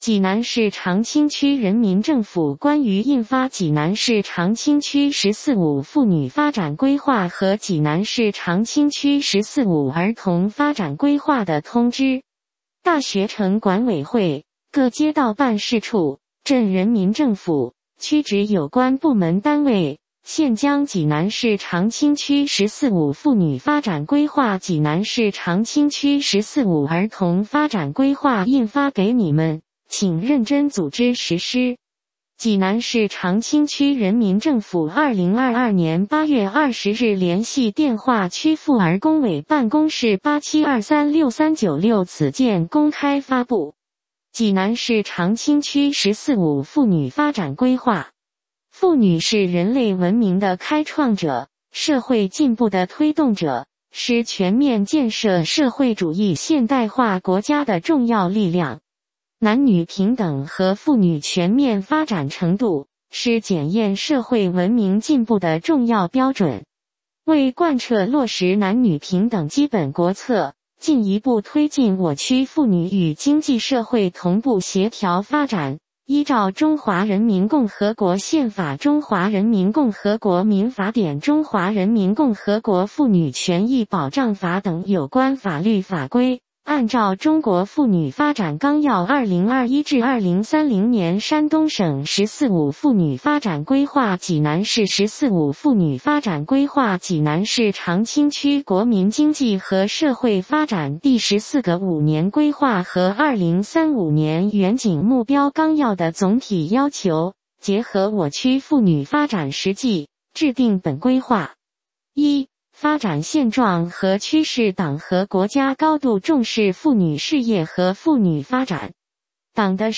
济南市长清区人民政府 - 有声朗读 - 有声朗读：济南市长清区人民政府关于印发济南市长清区“十四五”妇女发展规划和济南市长清区“十四五”儿童发展规划的通知